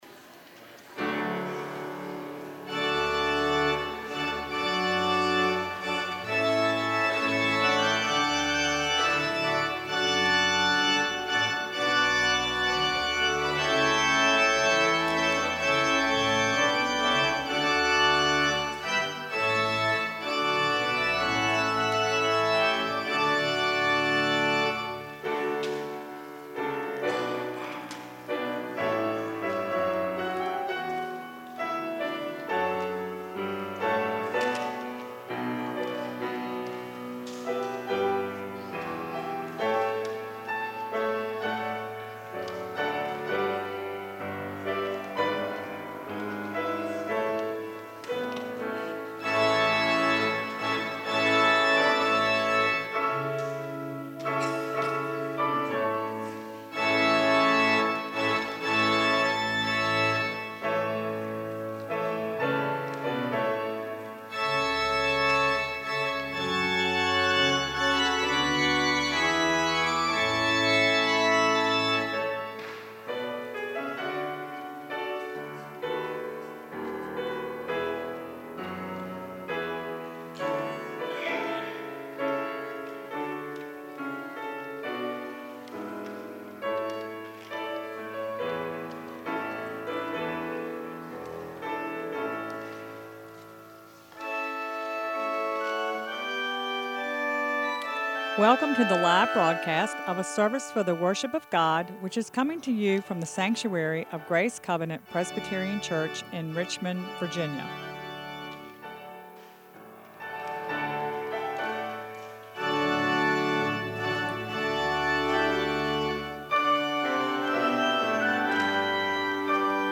piano
organ